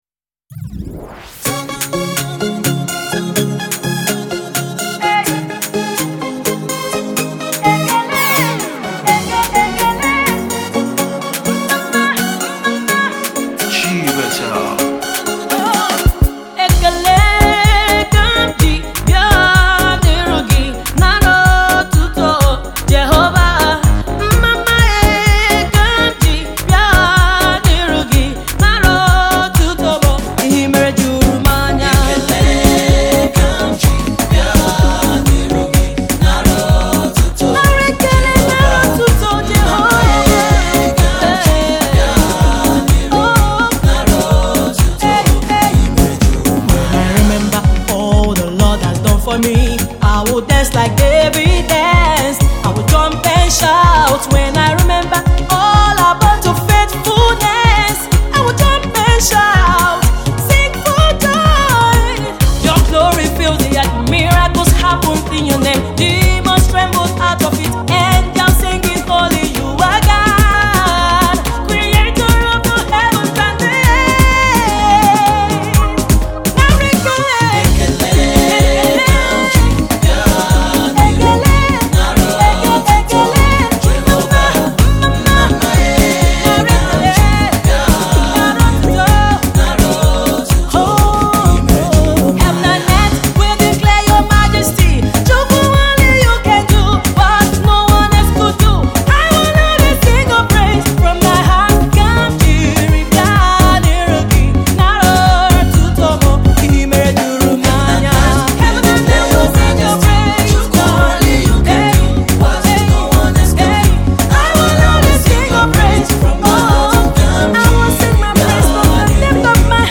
gospel songstress
thanksgiving song
She is one of the new gospel music rising stars.